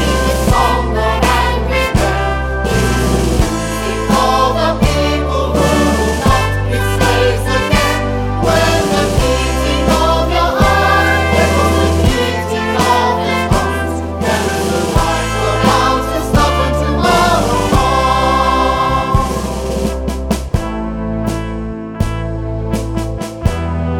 no Backing Vocals Musicals 2:10 Buy £1.50